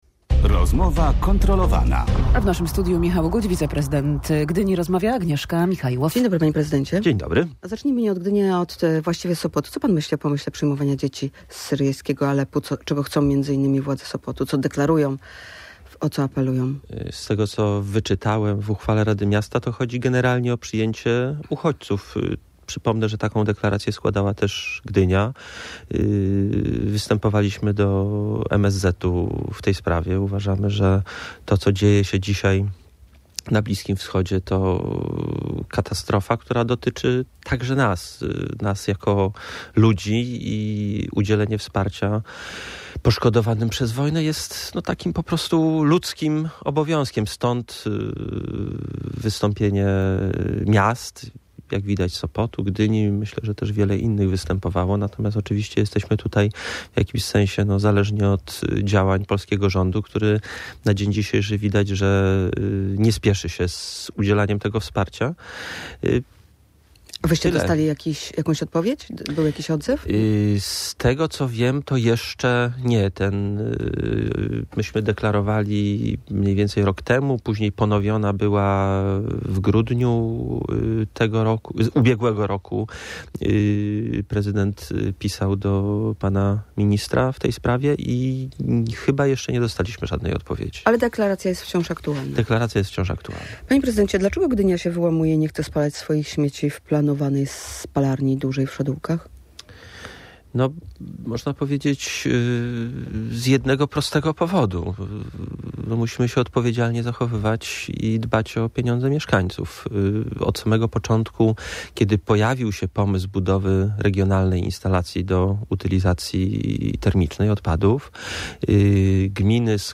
- Dlaczego Gdynia wyłamuje się i nie chce spalać swoich śmieci w planowanej spalarni w Szadółkach - pyta prowadząca audycję
Na jej pytanie odpowiada wiceprezydent Gdyni Michał Guć.